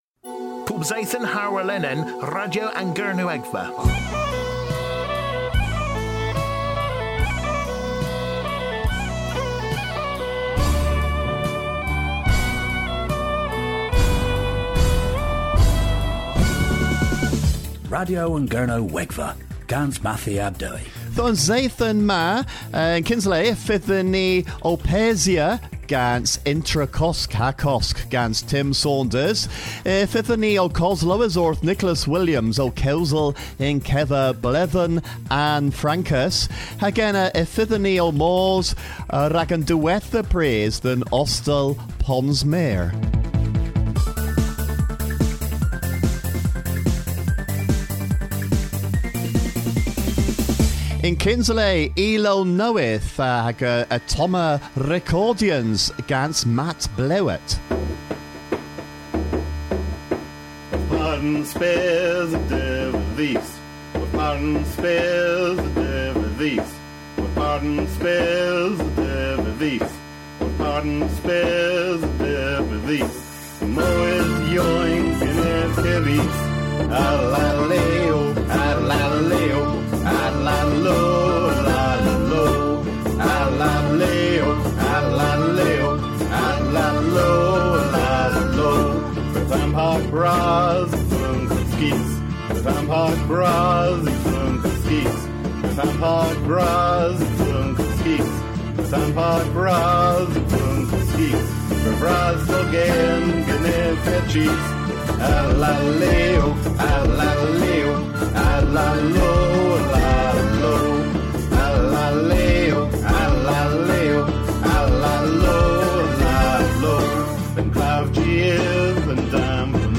Ni a lever farwel dhe Ostel Ponsmeur ha goslowes orth meur a ilow nowydh.